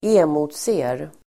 Ladda ner uttalet
Uttal: [²'e:mo:tse:r]